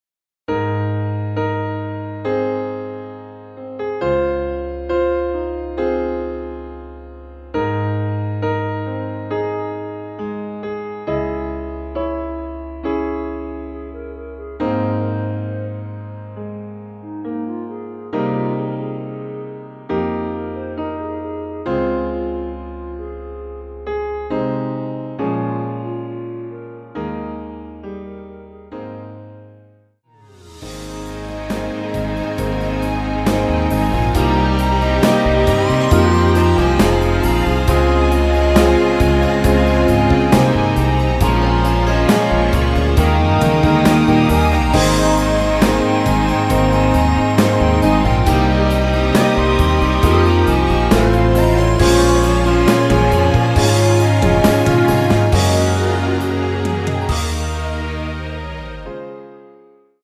원키에서(+2)올린 멜로디 포함된 MR입니다.
Ab
앞부분30초, 뒷부분30초씩 편집해서 올려 드리고 있습니다.
중간에 음이 끈어지고 다시 나오는 이유는